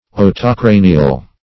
otocranial - definition of otocranial - synonyms, pronunciation, spelling from Free Dictionary Search Result for " otocranial" : The Collaborative International Dictionary of English v.0.48: Otocranial \O`to*cra"ni*al\, a. (Anat.) Of or pertaining to the otocrane.
otocranial.mp3